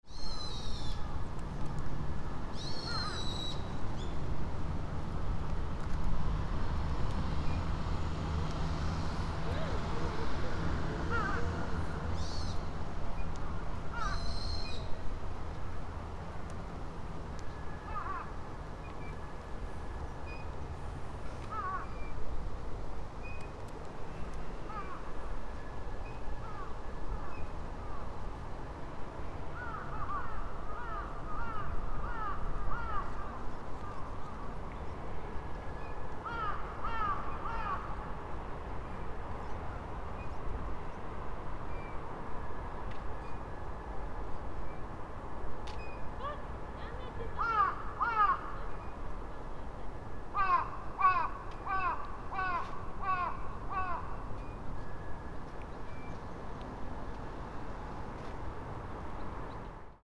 A boy and his mother were playing with a swing at Mt. Shinobu Park just before dusk. ♦ Crows were cawing lively, and some other birds were also twittering. ♦ The Kagura music broadcasted at Gokoku Shrine next to this park could be heard.